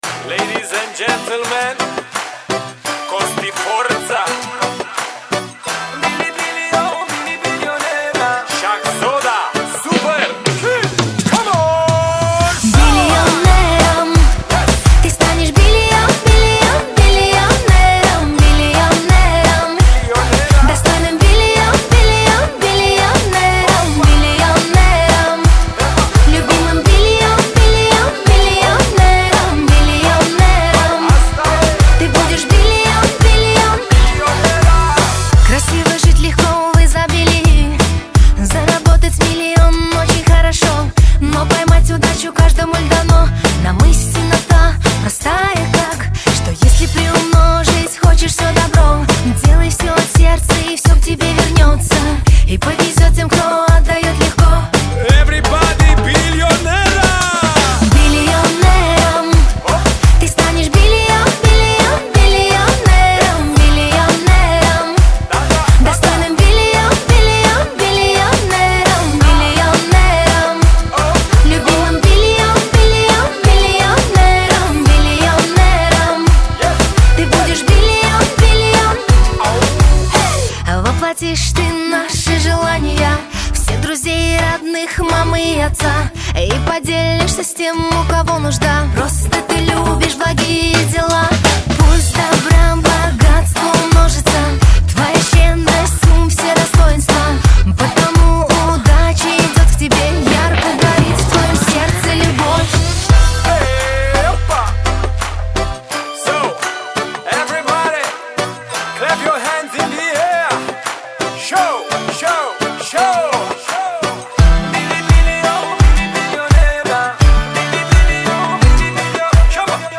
• Жанр: New Euro / Зарубежные песни